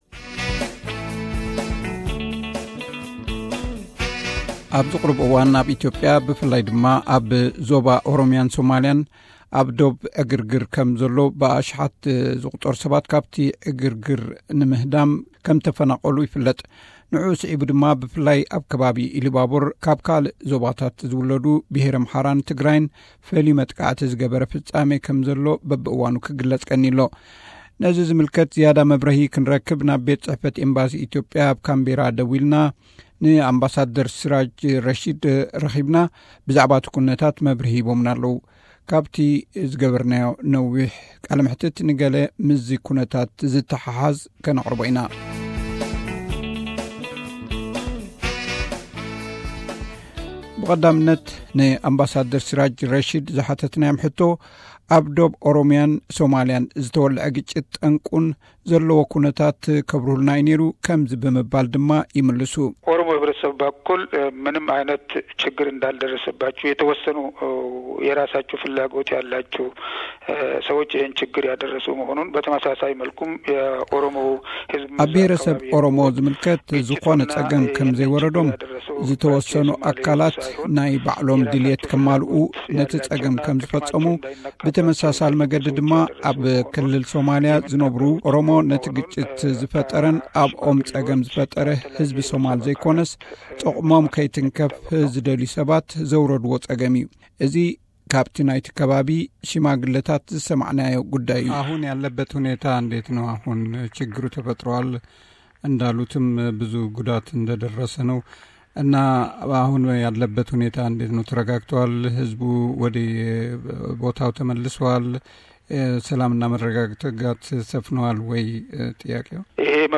Interview with Amba Siraj rashid